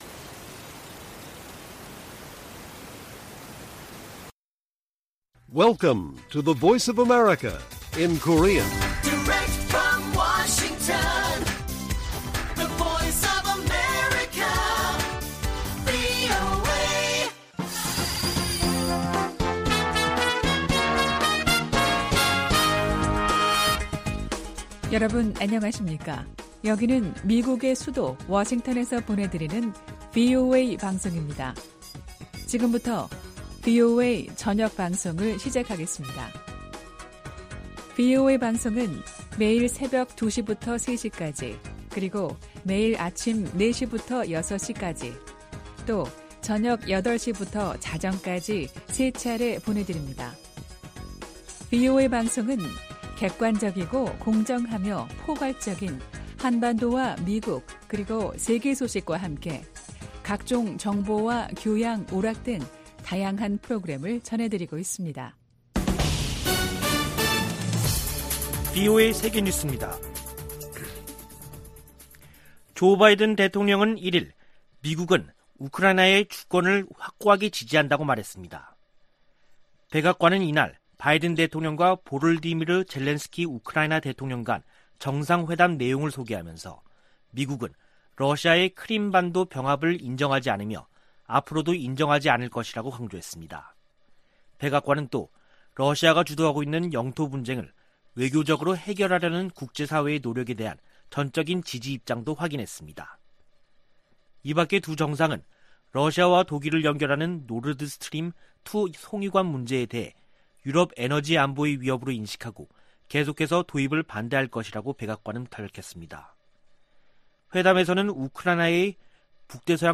VOA 한국어 간판 뉴스 프로그램 '뉴스 투데이', 1부 방송입니다. 미 국무부가 미국인들의 북한 여행금지 조치를 1년 더 연장하기로 했습니다. 미국의 구호 단체들과 이산가족 단체들은 북한 여행금지 재연장에 실망을 표시했습니다. 미 공화당 의원들은 영변 핵 시설 재가동은 북한이 미국과 선의로 비핵화 협상을 할 의향이 없다는 증거라며, 북한에 최대 압박을 가해야 한다고 바이든 행정부에 촉구했습니다.